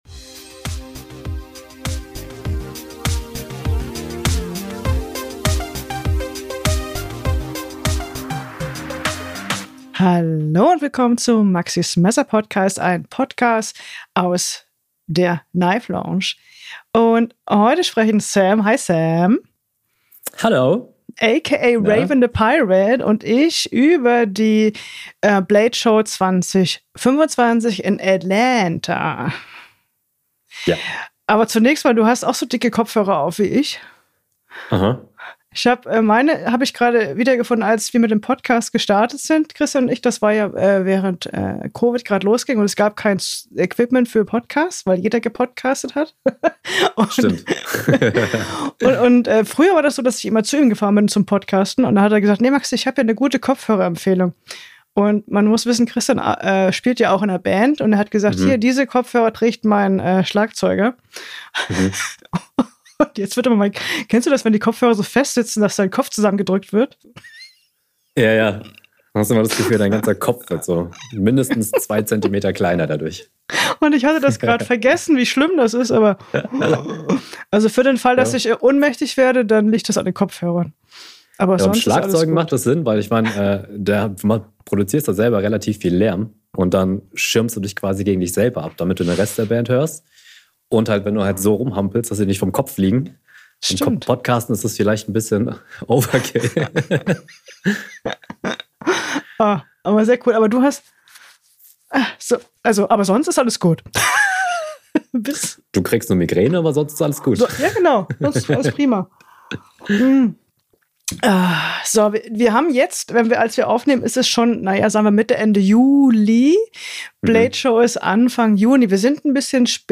Exklusive Einblicke und Gespräche von der größten Messerausstellung der Welt